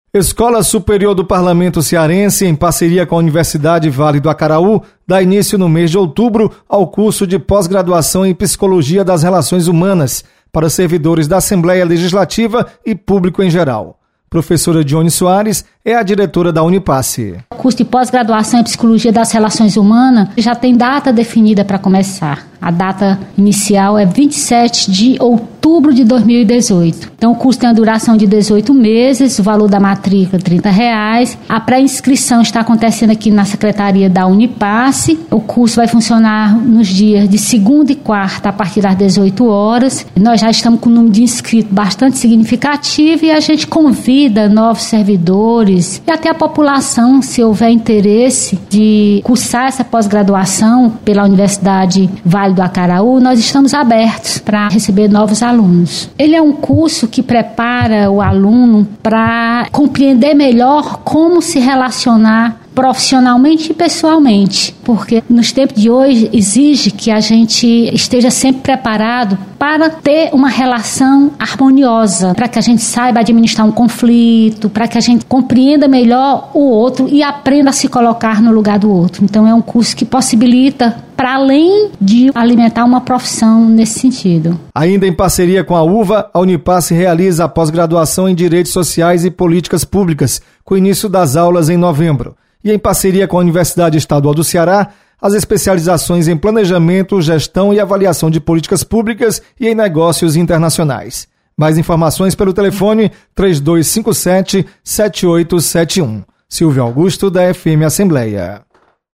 Unipace dá início ao curso de Pós-Graduação em  Relações Humanas. Repórter